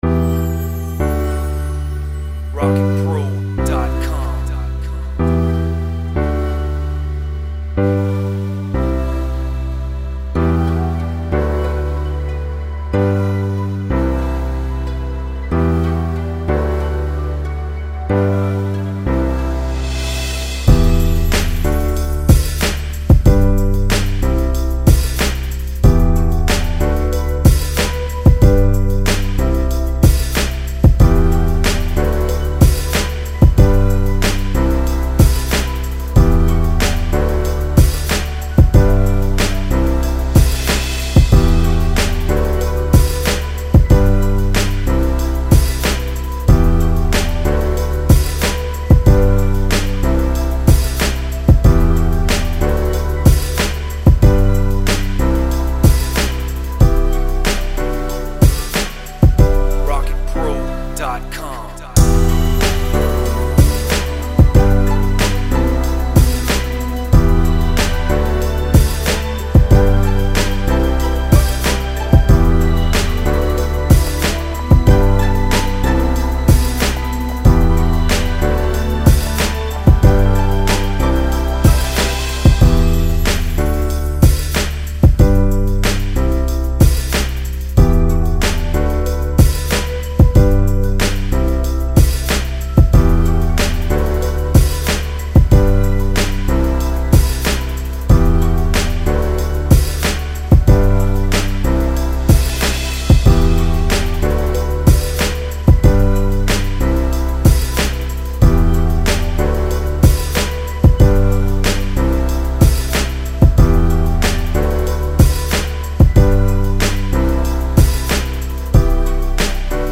84 BPM.